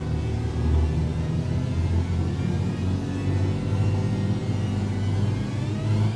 speed_up_1.ogg